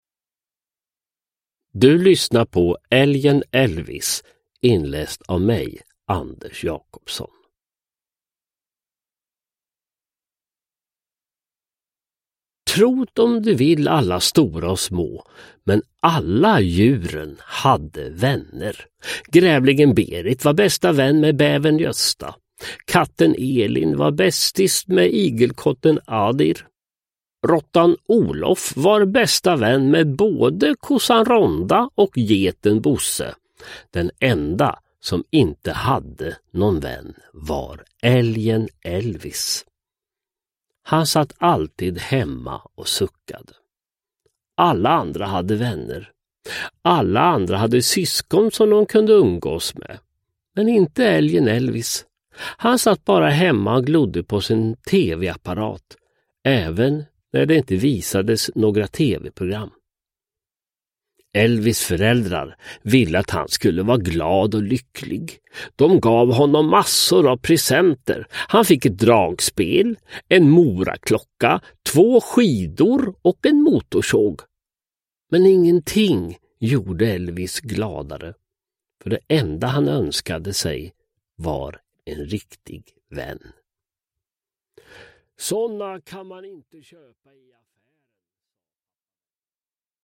Älgen Elvis – Ljudbok – Laddas ner
Uppläsare: Sören Olsson, Anders Jacobsson